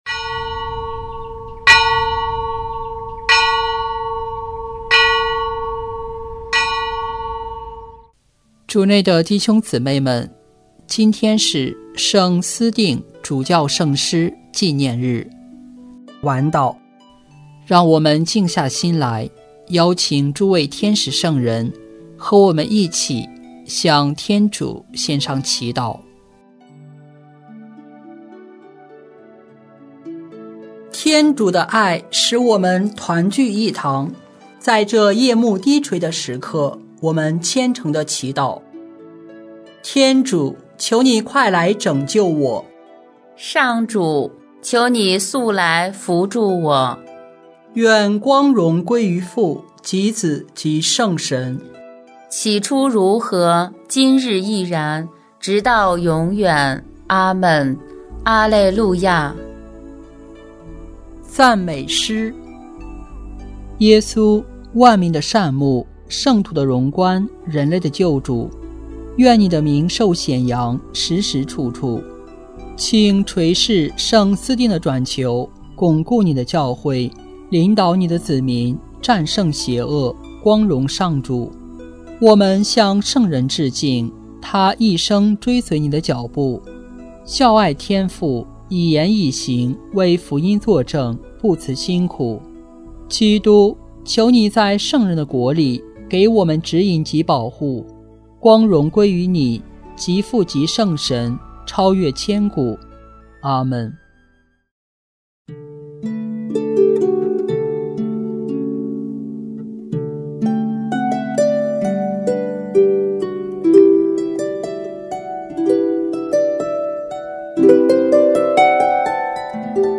【每日礼赞】|8月28日圣思定主教圣师纪念日晚祷